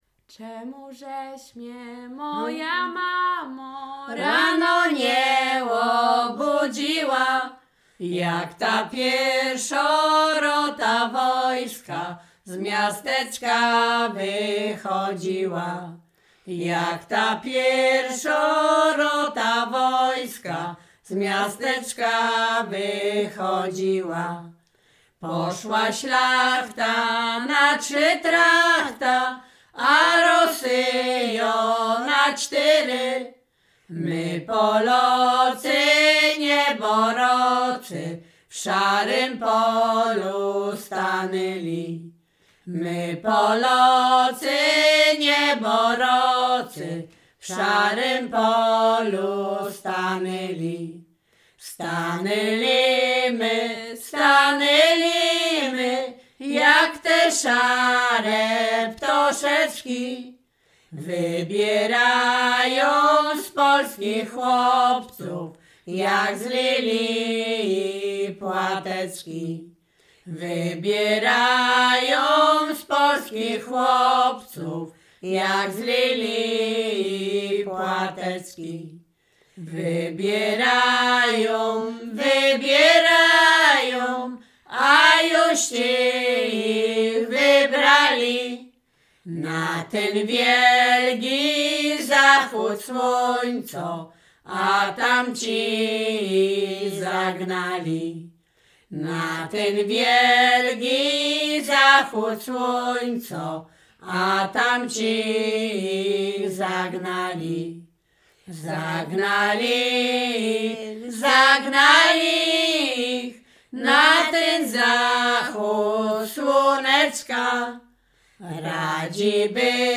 ballady liryczne wojenkowe rekruckie